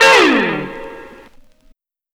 Track 15 - Guitar Slide 02.wav